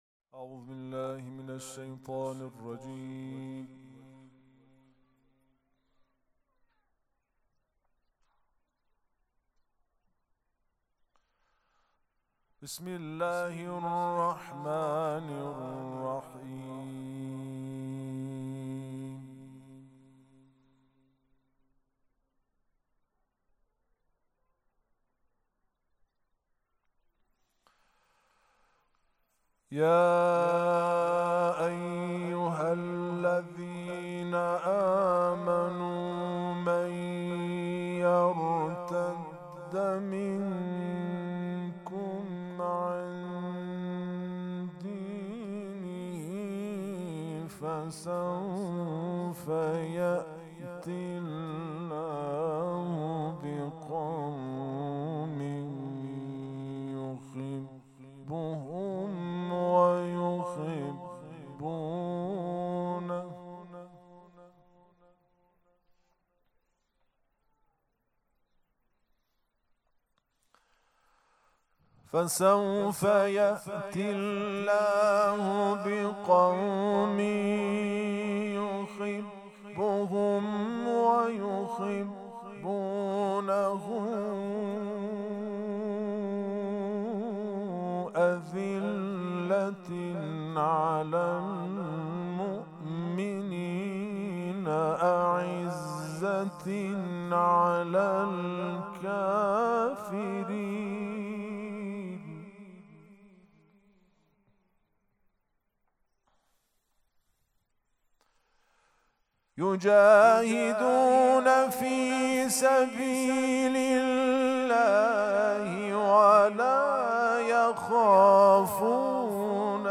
قرائت قرآن کریم
سبک اثــر قرائت قرآن